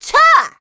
assassin_w_voc_attack01_h.ogg